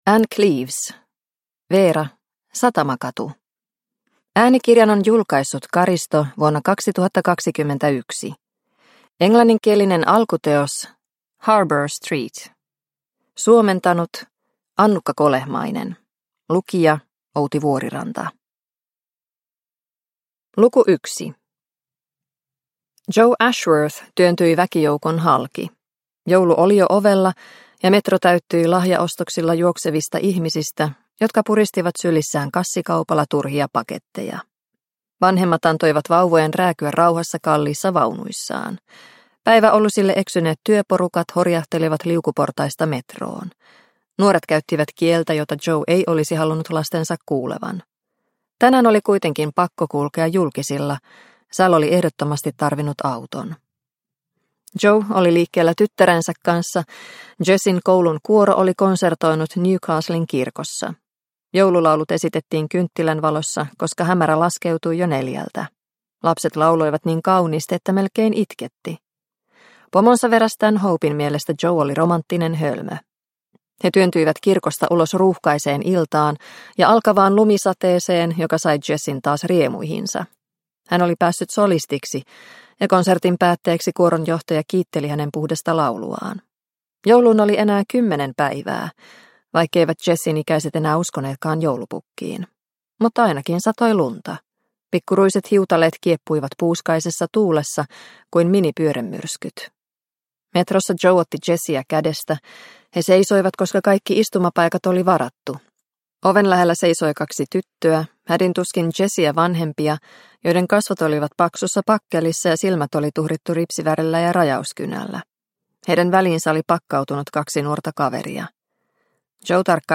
Satamakatu – Ljudbok – Laddas ner